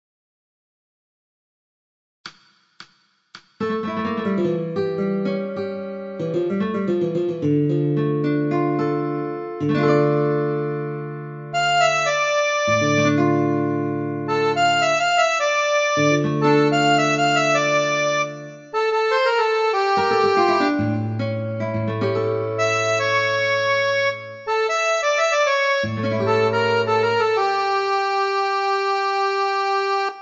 • Backing Track: Midi File